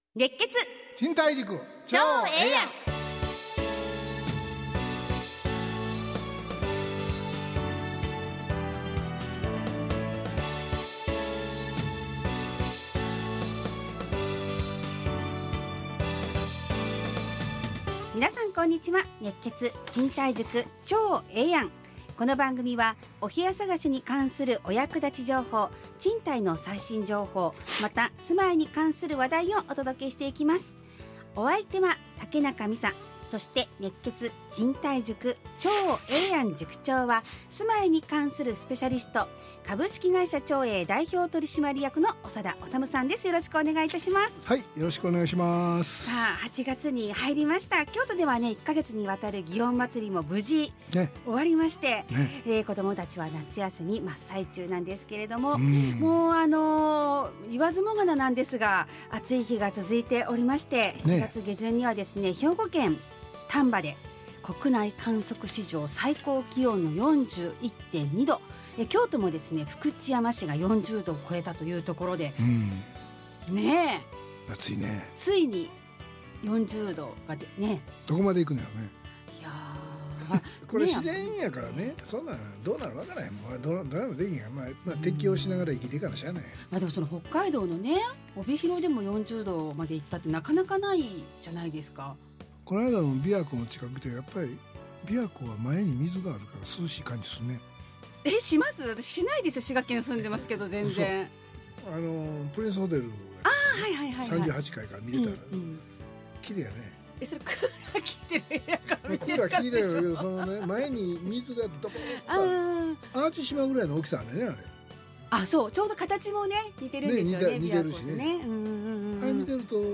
ラジオ放送 2025-08-01 熱血！